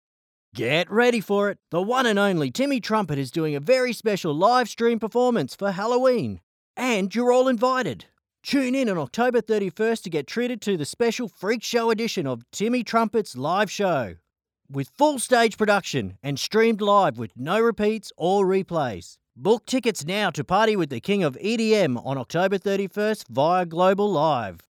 Male
English (Australian)
Yng Adult (18-29), Adult (30-50)
Radio Commercials
Fast Paced And Energetic